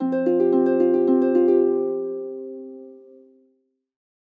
RR_ringtone.wav